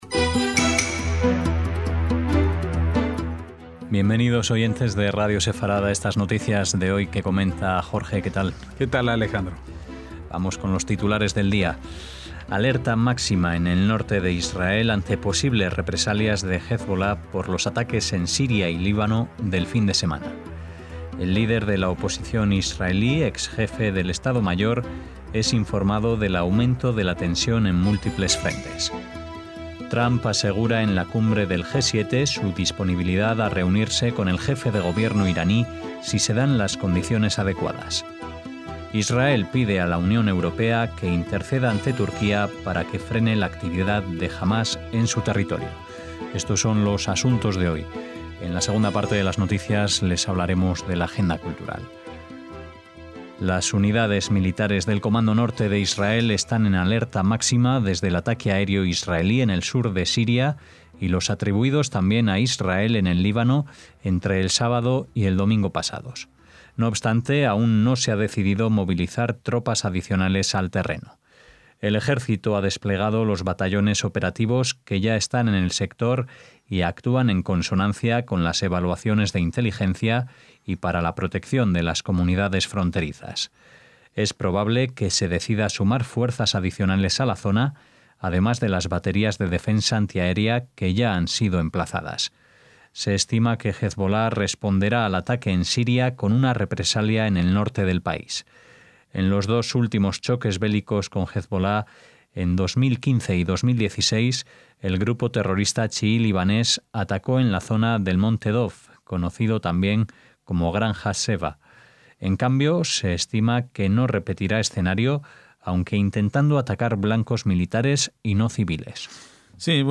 NOTICIAS - Titulares de hoy: Alerta máxima en el norte de Israel ante posibles represalias de Hezbolá por los ataques en Siria y Líbano del fin de semana.